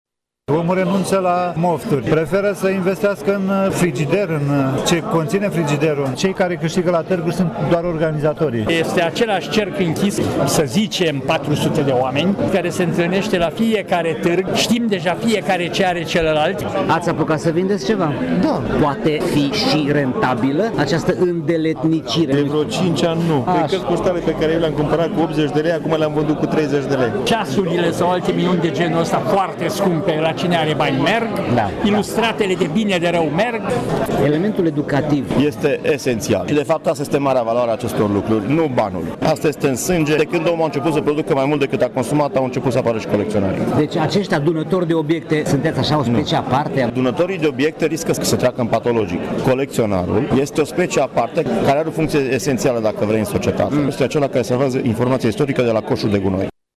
Colecționarul este cel care salvează informația istorică de la coșul de gunoi, crede unul dintre expozanții prezenți la Târgul Colecționarilor, organizat astăzi la Casa de Cultură ”M. Eminescu” din Tîrgu-Mureș.
Un colecționar din Cluj a adus la târg plicuri, vederi, documente vechi, timbre sau cutii. Aceste crede că nu există obiecte care să nu poate fi colecționate și nici reguli în acest domeniu al pasionaților de antichități: